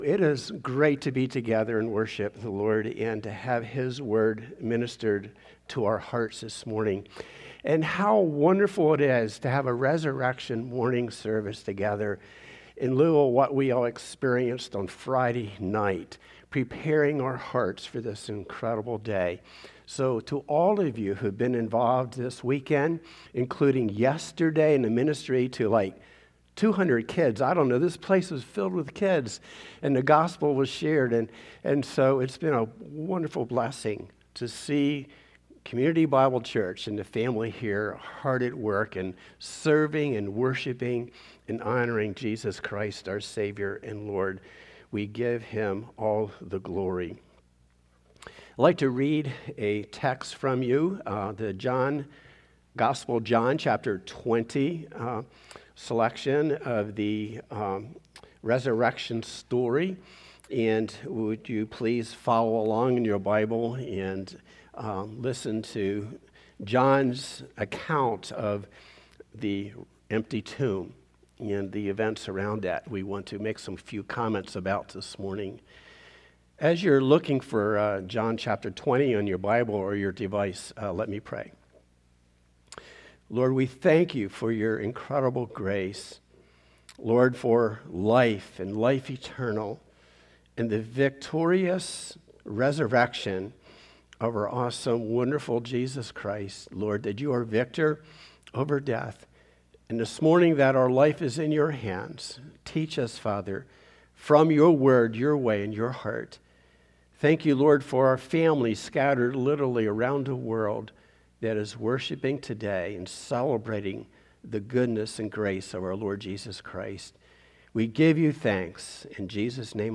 4-5-26-Sermon-Servant-Jesus-Celebrations.mp3